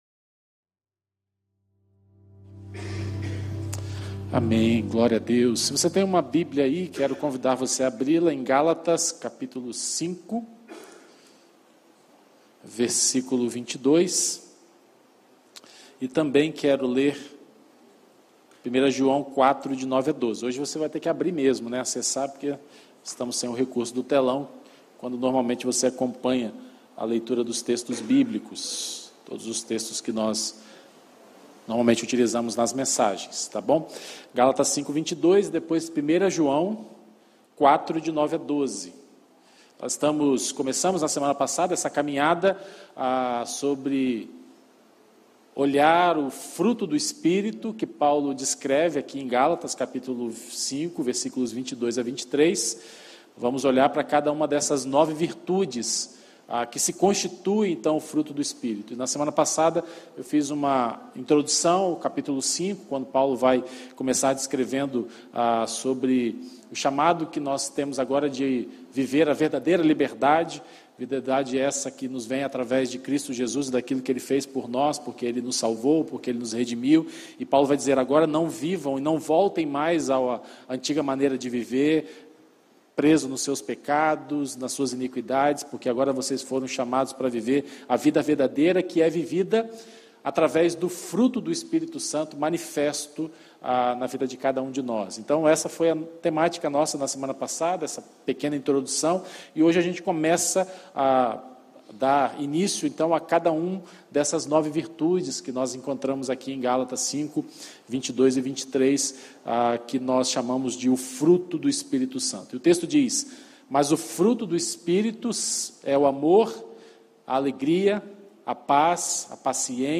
Primeira Igreja Batista do IPS